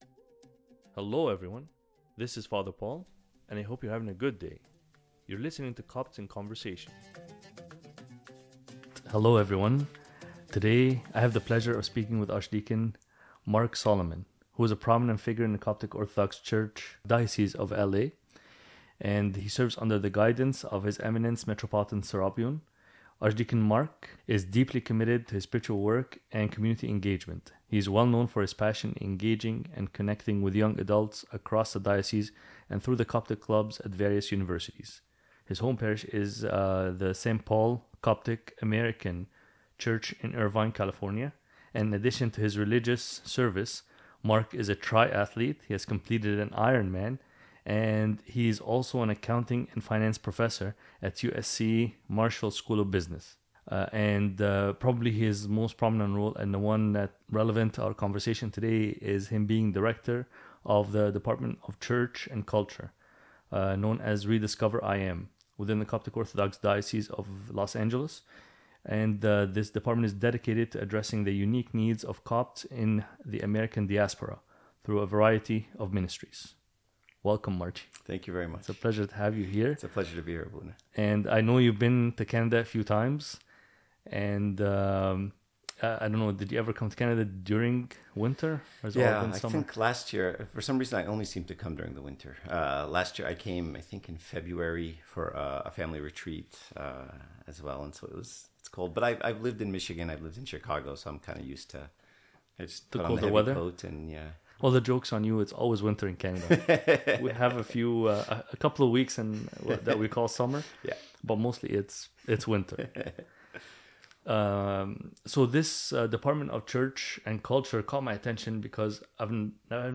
Copts in Conversation